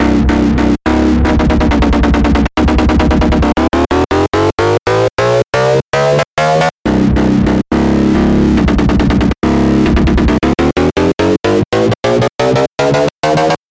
Tag: 140 bpm Dubstep Loops Bass Wobble Loops 2.31 MB wav Key : C